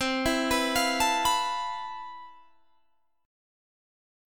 CM13 chord